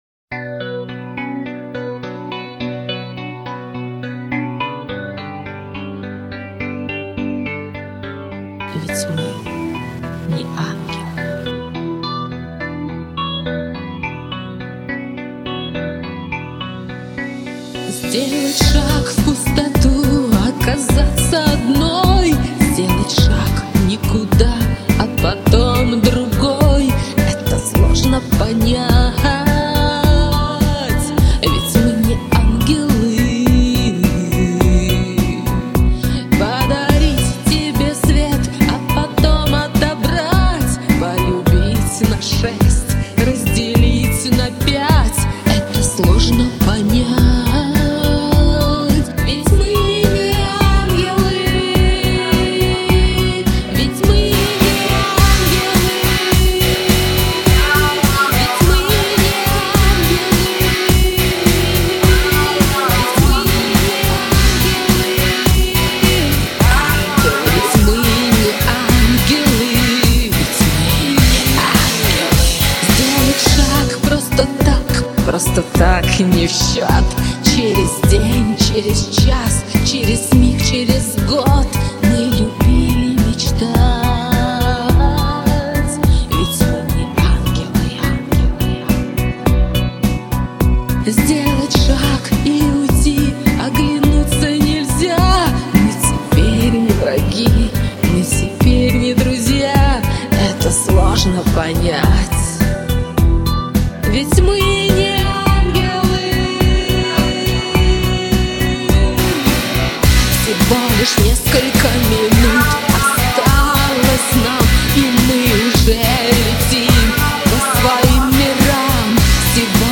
Сведение обоих вещей мое.